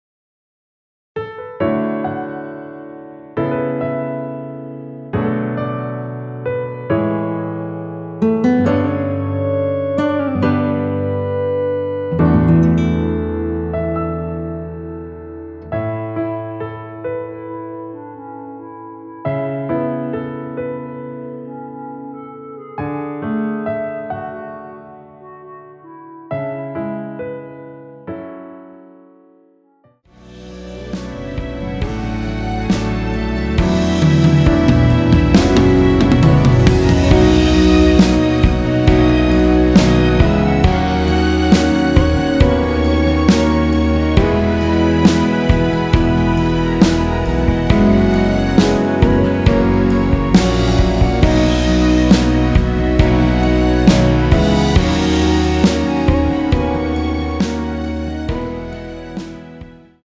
원키에서(-3)내린 멜로디 포함된 MR입니다.
앞부분30초, 뒷부분30초씩 편집해서 올려 드리고 있습니다.
중간에 음이 끈어지고 다시 나오는 이유는